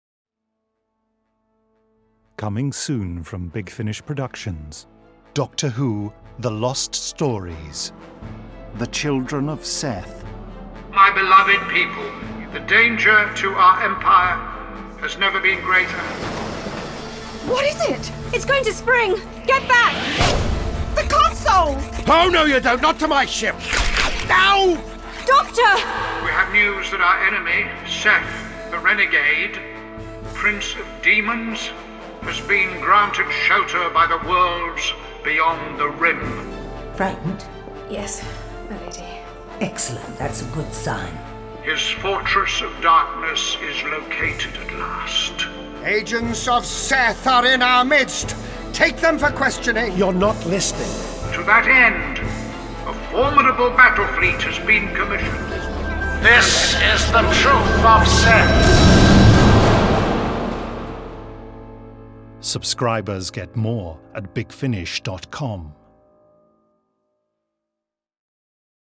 full-cast original audio dramas